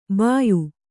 ♪ bāyu